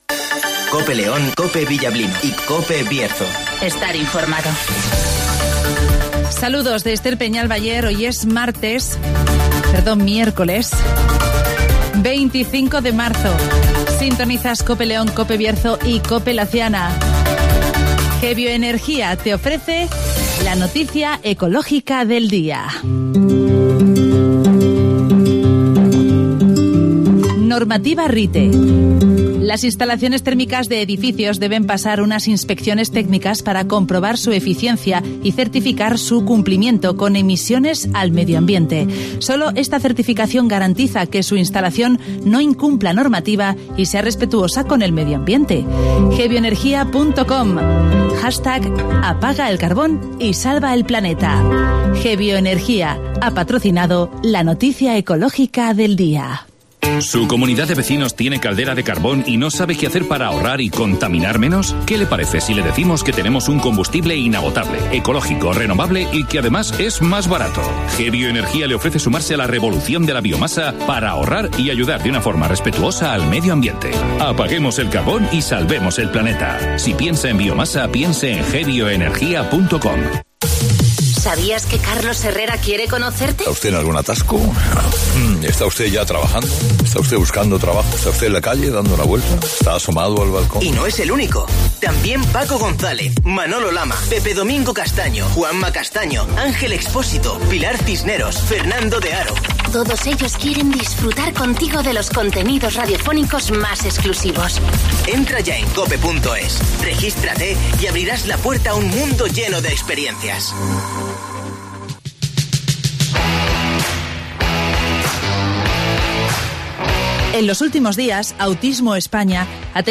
AUDIO: Repasamos la actualidad y realidad del Bierzo. Espacio comarcal de actualidad,entrevistas y entretenimiento.